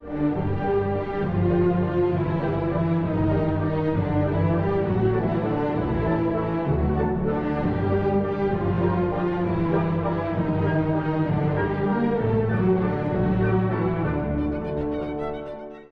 第2主題は、夜の墓場を思わせるような、重い幽玄な旋律
さらに特筆すべきは、当時としては非常に珍しかった木琴の起用
骨の擦れる音を再現し、聴き手にはさらなる非日常感を味わせているのです。